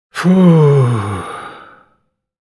Disappointed-sigh-sound-effect.mp3